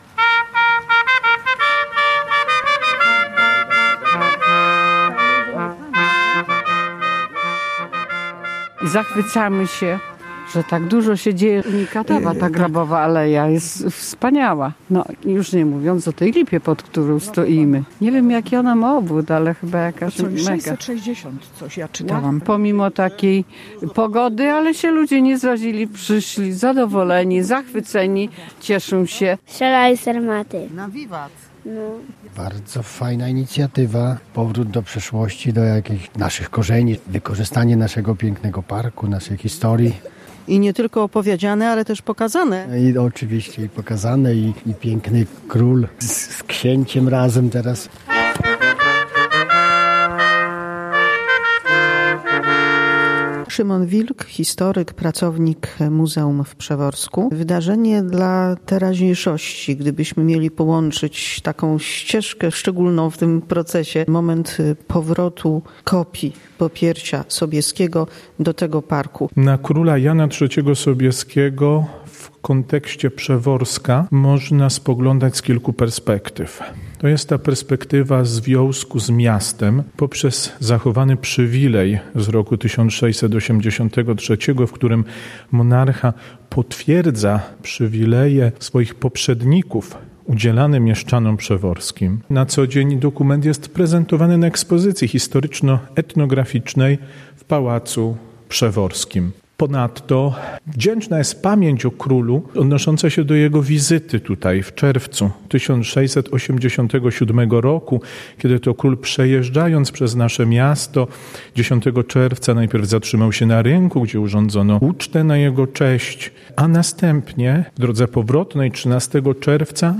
Popiersie Jana III Sobieskiego znów zdobi wejście do alei grabowej w parku przy Pałacu Lubomirskich w Przeworsku. Prezentacji towarzyszyła inscenizacja historyczna.